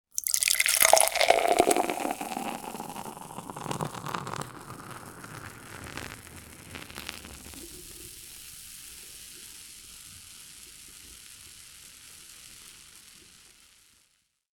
Beer-pouring-into-glass-sound-effect.mp3 341.6 Кб